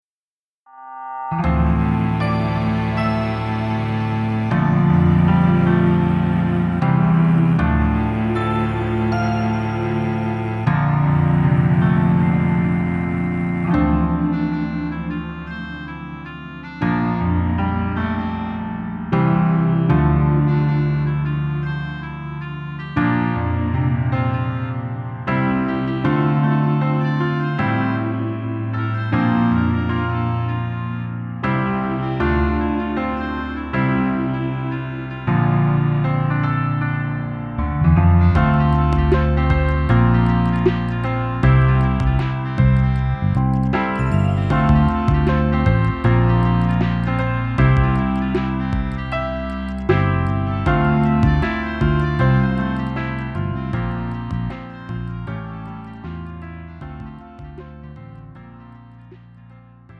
음정 남자키
장르 가요 구분 Pro MR